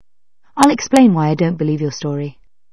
- نطق explain هو /ik splān/.
- نطق why هو /wī/ أو /hwī/.
- نطق believe هو / bi lēv /.
- نطق story هو / stawr ē/
- عند قراءة الجملة السابقة، فإن نبرة الصوت أو قوة الصوت ترتفع وتنخفض على حسب الكلمة وعلى حسب ما يريد أن يؤكده قائل الجملة.
- أما الكلمات أو المقطع التي باللون الرمادي فبالكاد ستسمعها.